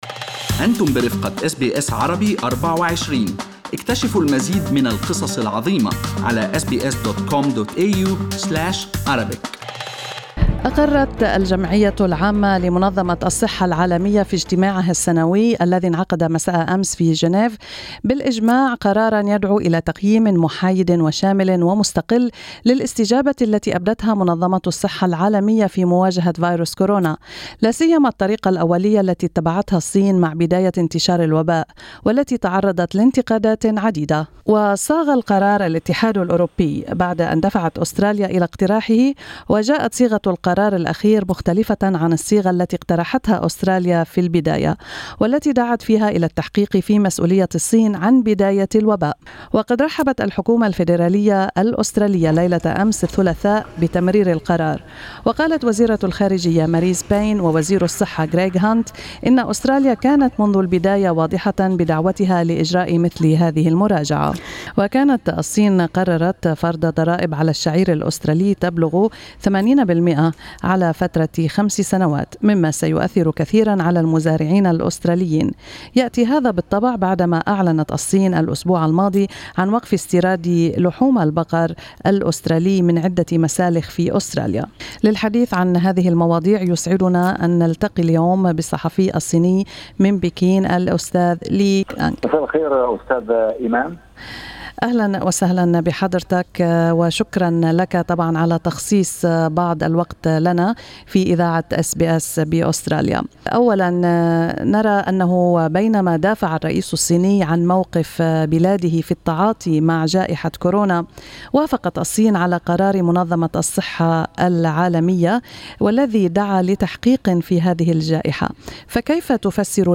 صحفي صيني يتحدث العربية: الصين لا تعاقب أستراليا